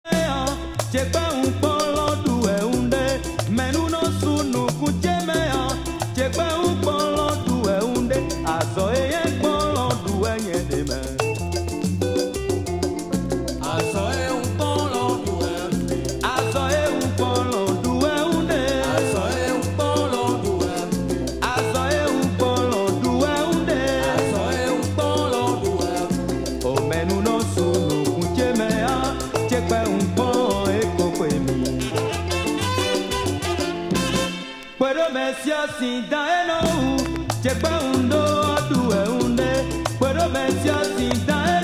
1979 Genero: Latin, Afro-Cuban